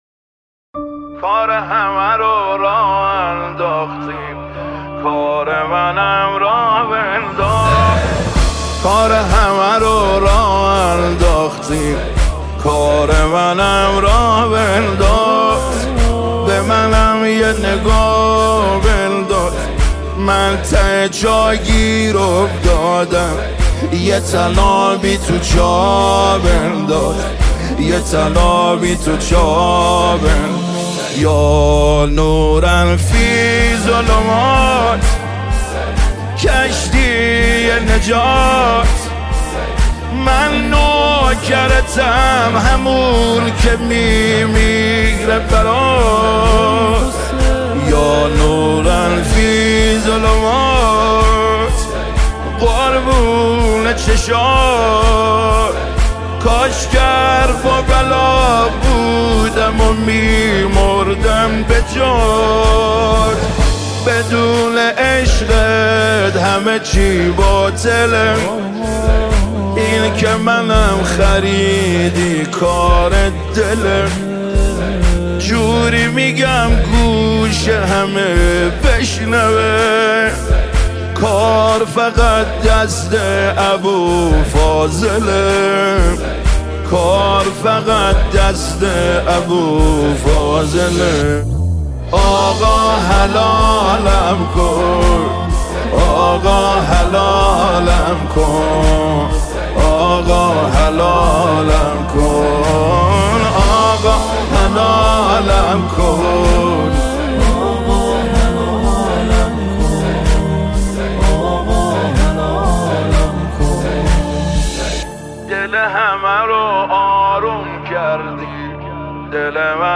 با نوای دلنشین